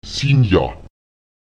mn [ø] wird wie das nj in Anja artikuliert.
Lautsprecher simna [Èsiøa] der Stern